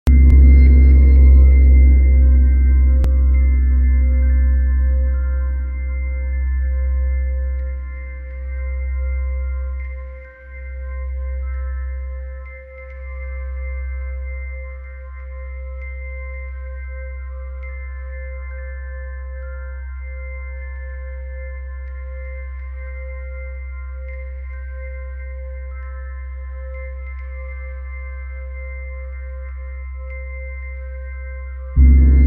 528Hz is called the Love Frequency for its calming, harmonizing energy.